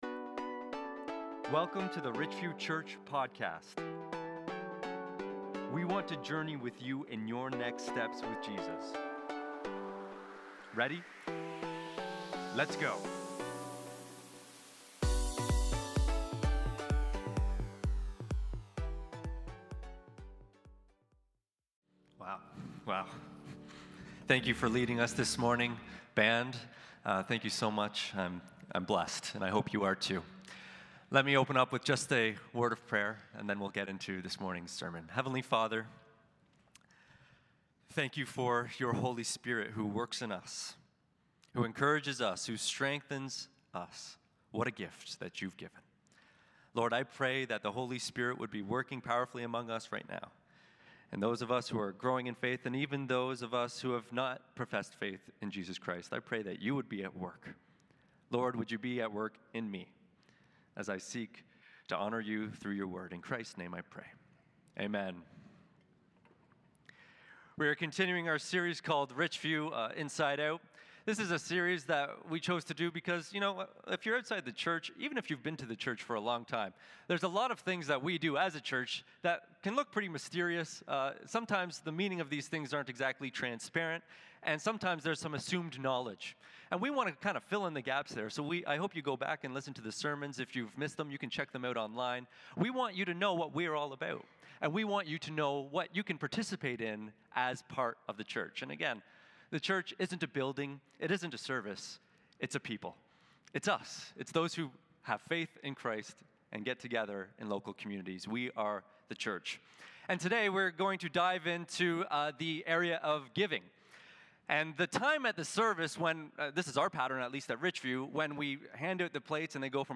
2 Corinthians 9:6-15 💻 Sermon